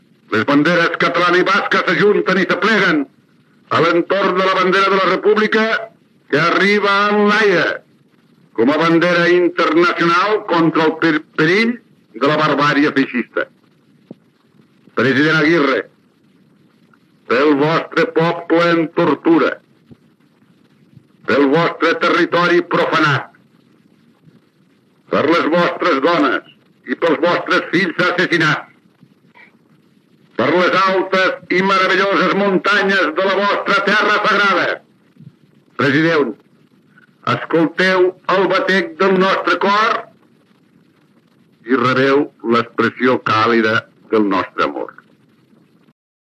Discurs de benvinguda del president de la Generalitat Lluís Companys al lehendakari José Antonio Aguirre.
Enregistrament original en disc de pasta que es troba digitalitzat a la Biblioteca de Catalunya.